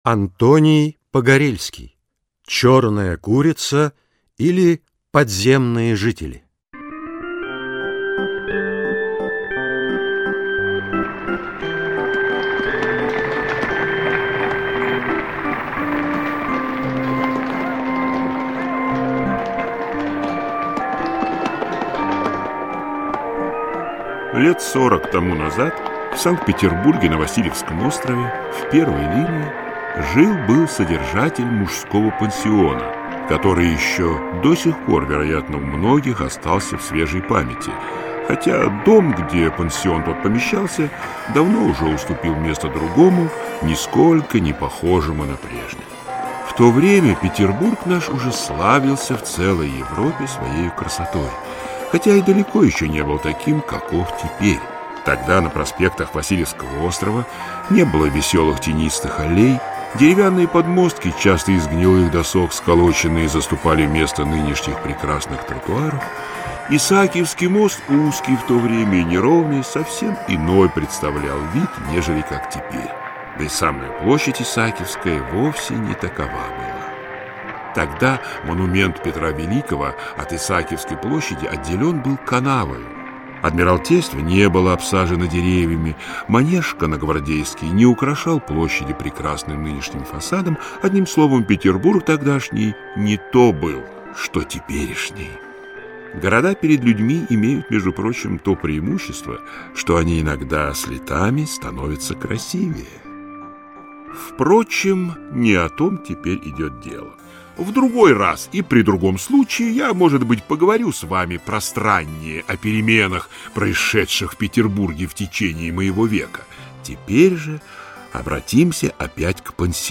Аудиокнига Черная курица и др. сказки русских писателей | Библиотека аудиокниг